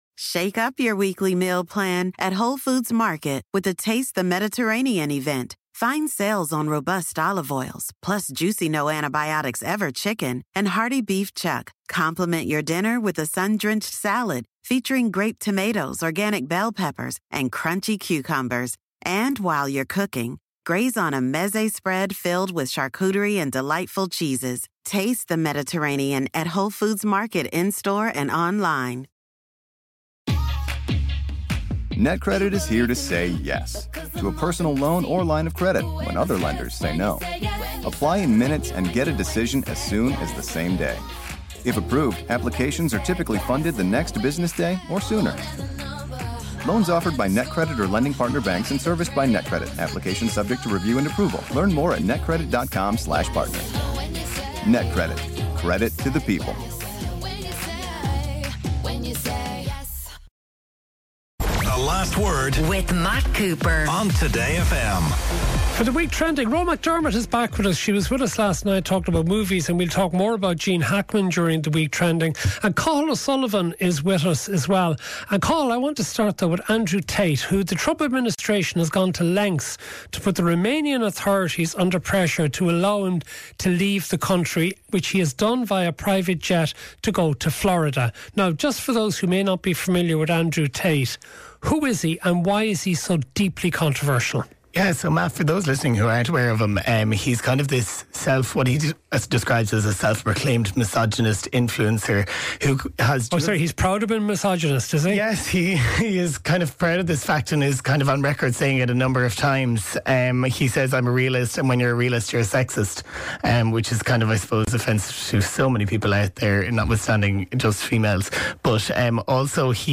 On Ireland's most cutting edge current affairs show, Matt and his guests provide a running stream of intelligent opinions and heated debates on the issues that matter most to Irish listeners.